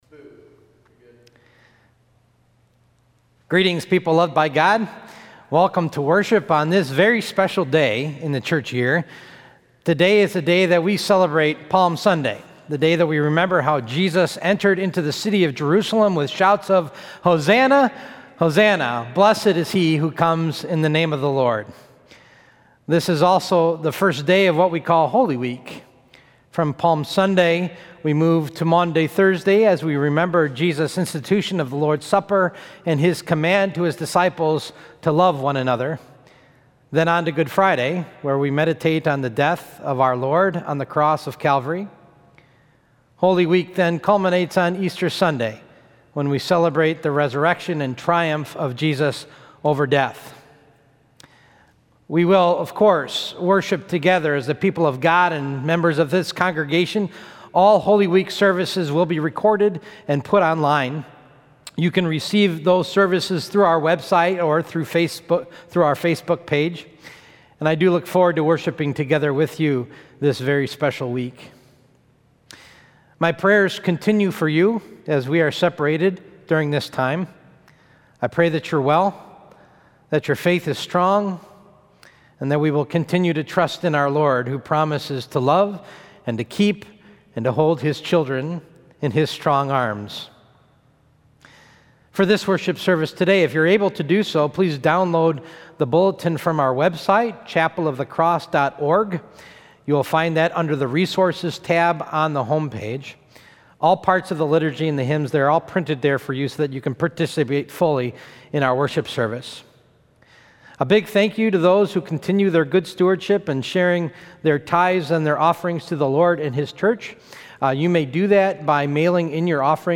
Sunday Worship 4-5-20 (Palm Sunday)